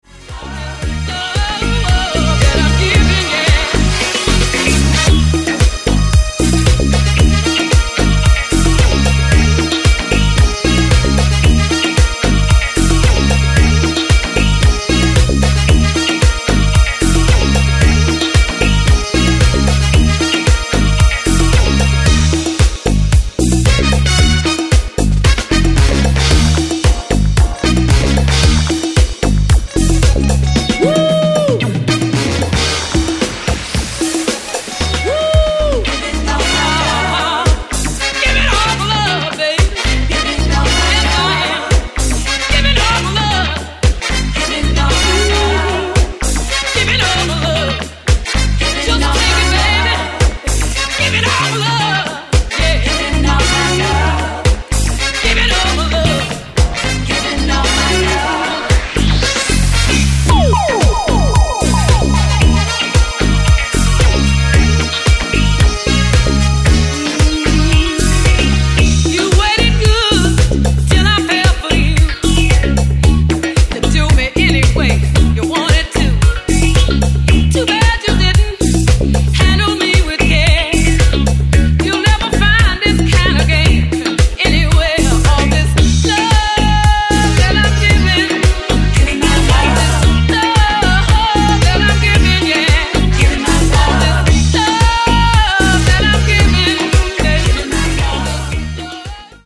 4 danceable boogie disco re-edits